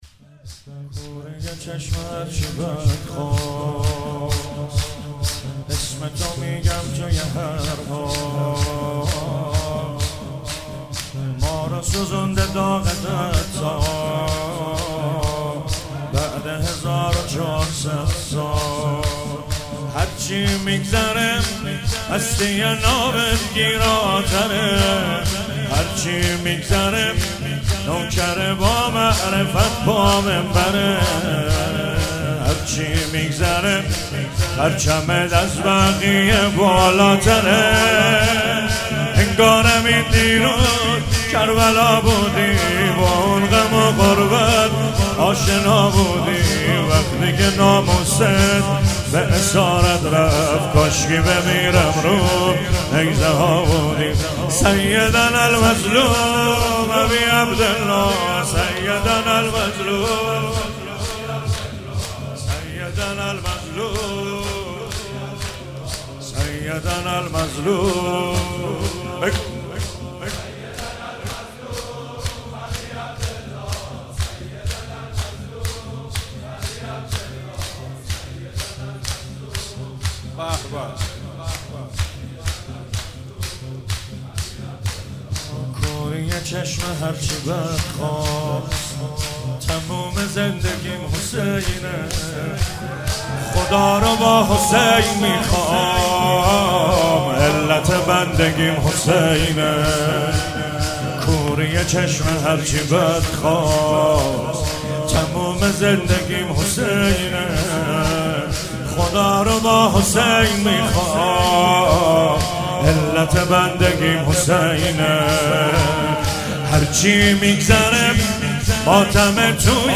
هفتگی 6 دی 97 - شور - کوری چشم هرچی بدخواه